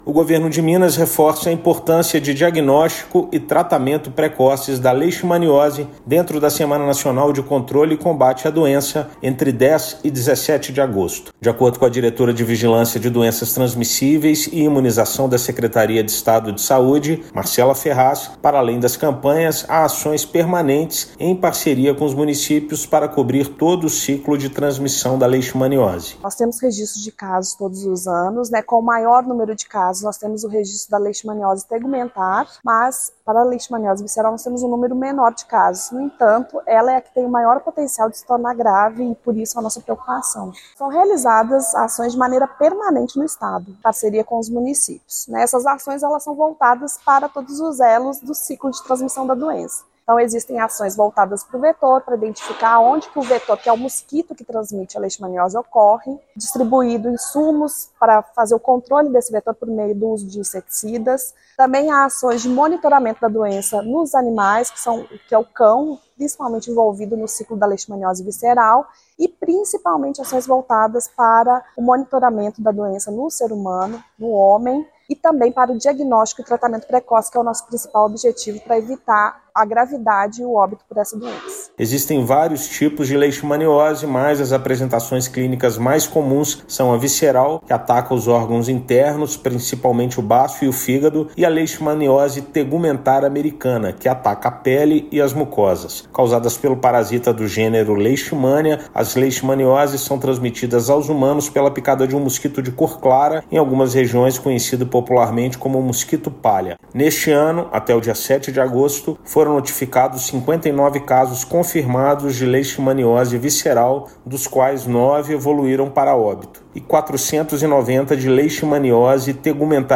Semana Nacional de Controle e Combate à doença ocorre de 10 a 17/8 com o objetivo de ampliar cuidados e conscientização da população. Ouça matéria de rádio.